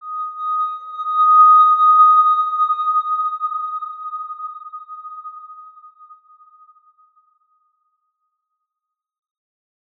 X_Windwistle-D#5-ff.wav